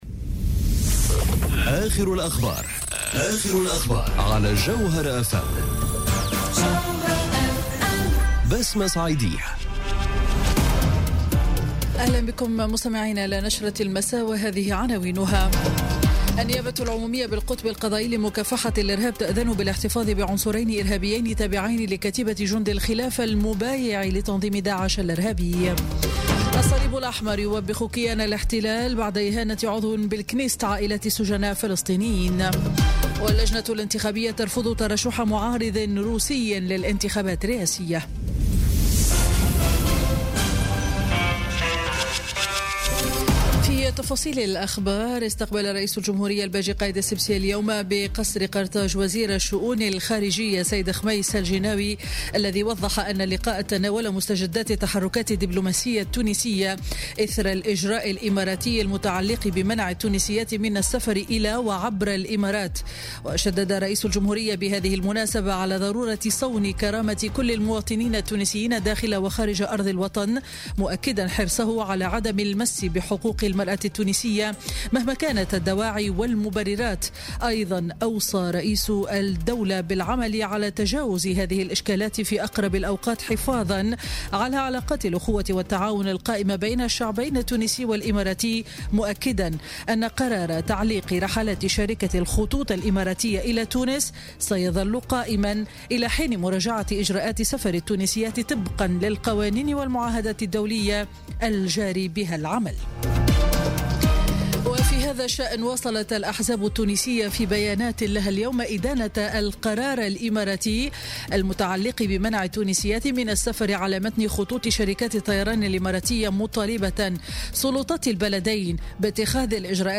Journal Info 19h00 du lundi 25 décembre 2017